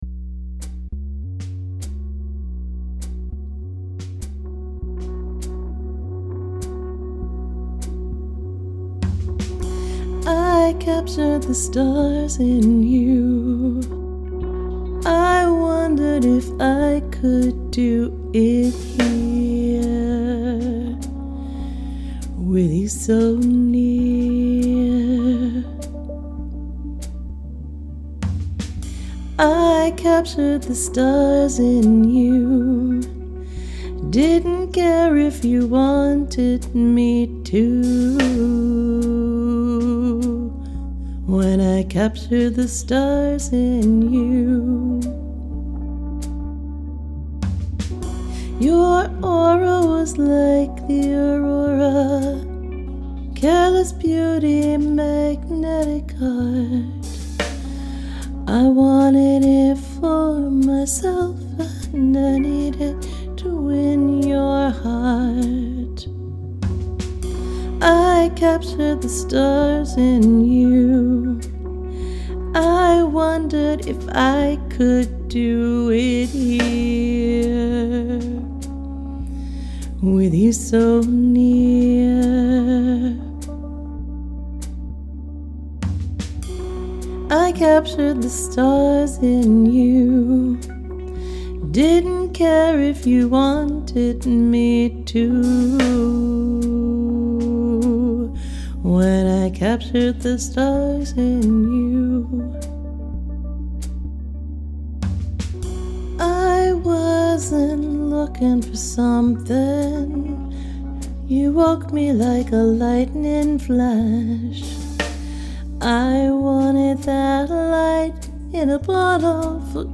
I intended to just submit that version in its raw, single take glory but the technical gods weren’t letting that happen so instead I decided I’d see what happened if I sat down and recorded it away from the stove.
I’m glad that I did because this little swingy jazz thing started to happen and it wouldn’t have otherwise.